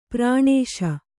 ♪ prāṇēśa